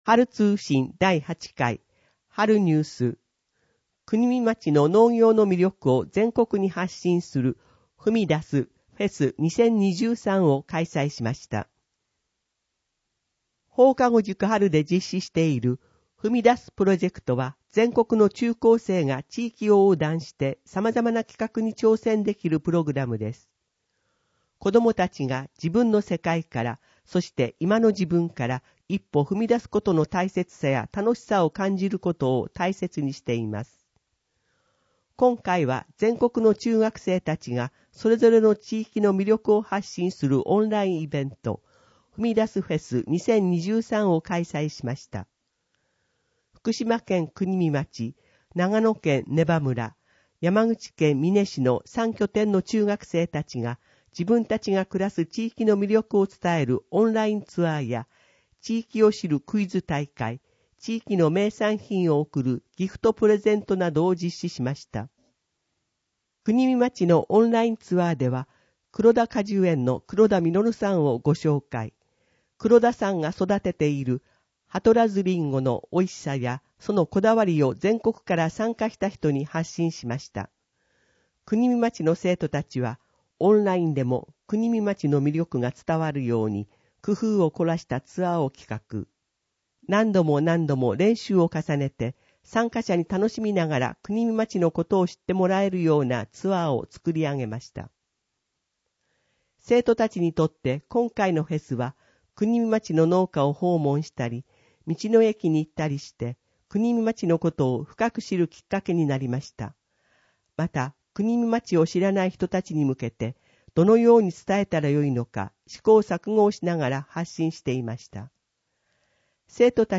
＜外部リンク＞ 声の広報 声の広報⑴ [その他のファイル／9.4MB] 声の広報⑵ [その他のファイル／8.86MB] 声の広報⑶ [その他のファイル／8.18MB]